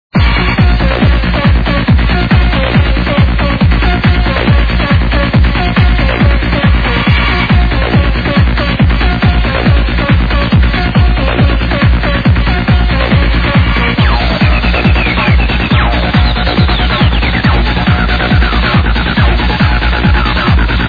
The TB-303 in this song is MASSIVE!!
German Hard Trance, Needs a ID!
Good acid stuff from one of my those day